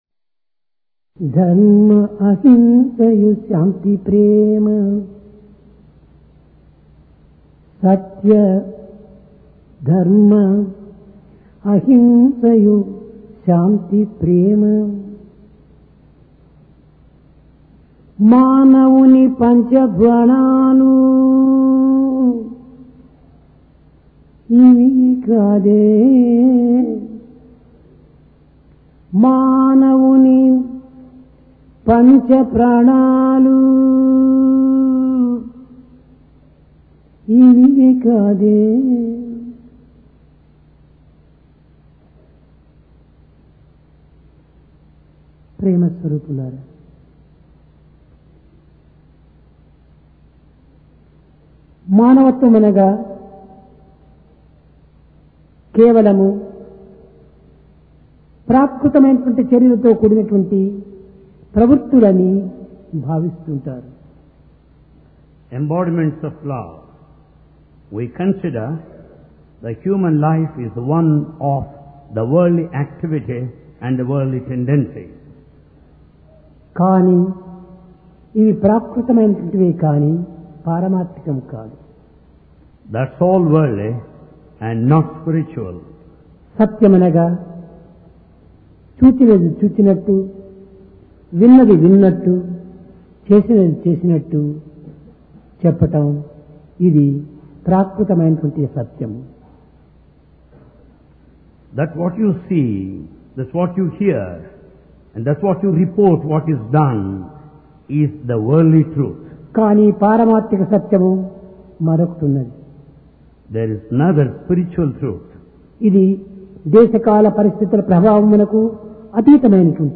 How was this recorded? Sai Darshan Home Date: 2 Jul 1996 Occasion: Divine Discourse Place: Prashanti Nilayam Truth And Its Practice We consider human life the one of worldly activity and tendency.